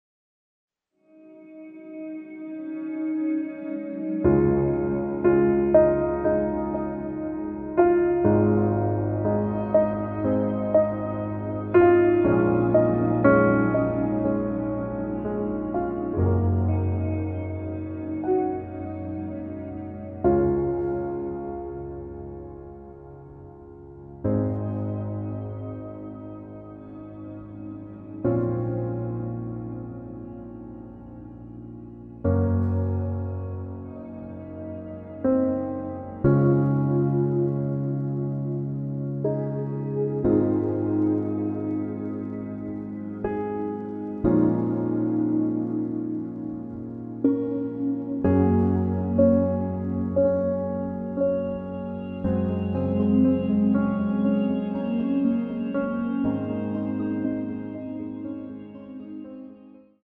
공식 음원 MR
Db
앞부분30초, 뒷부분30초씩 편집해서 올려 드리고 있습니다.
중간에 음이 끈어지고 다시 나오는 이유는